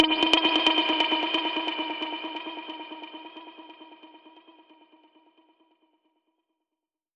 Index of /musicradar/dub-percussion-samples/134bpm
DPFX_PercHit_C_134-08.wav